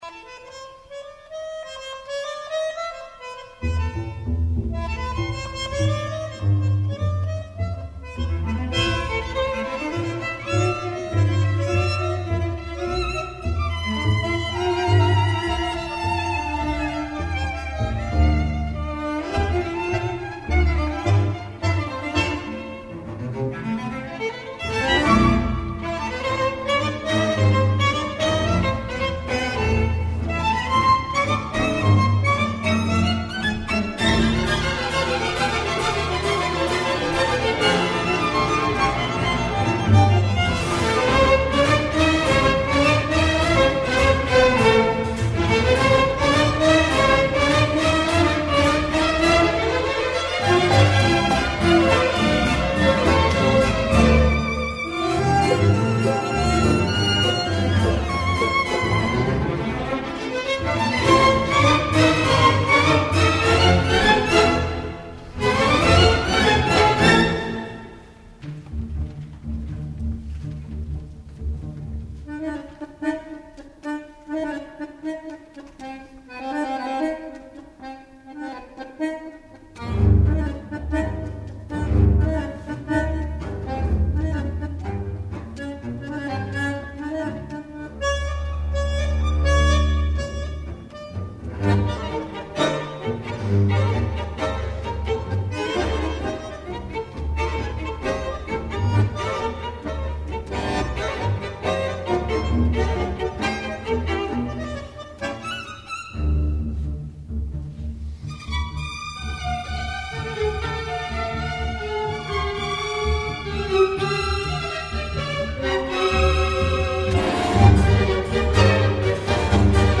für Tangoensemble und Orchester